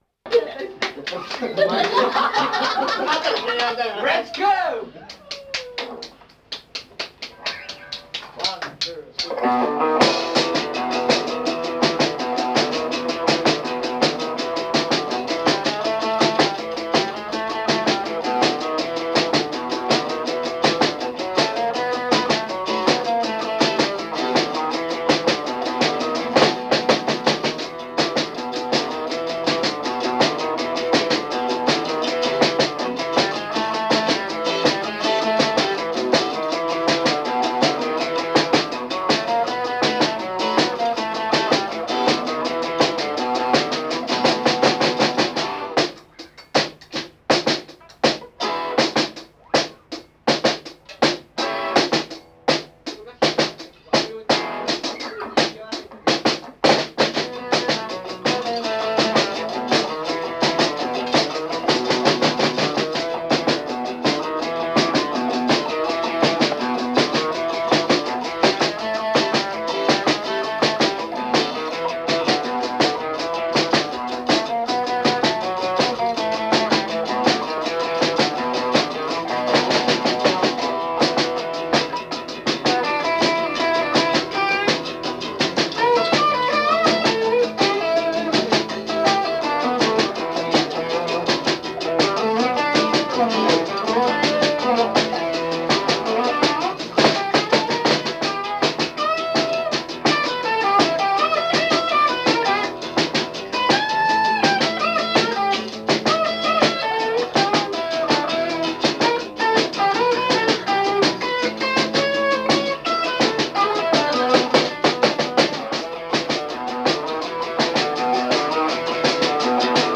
正月だけのライヴバンド“ＡＫＴ”